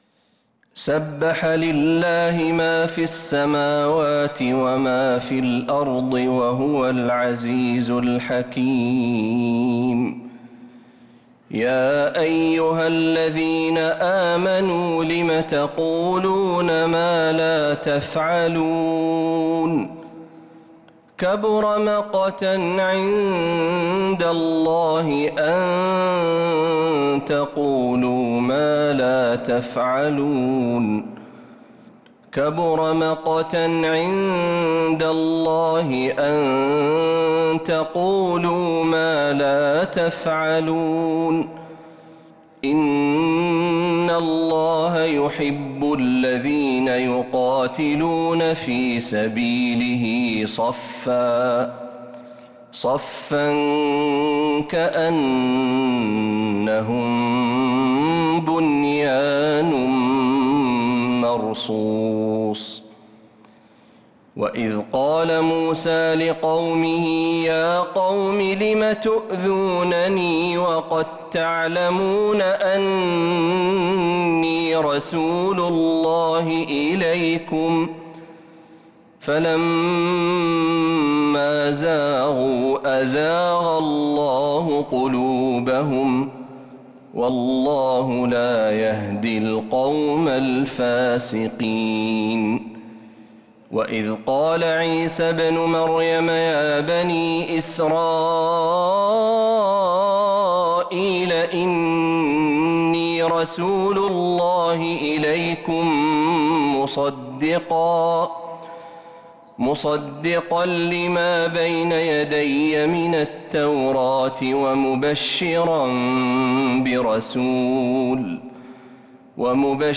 سورة الصف كاملة من عشائيات الحرم النبوي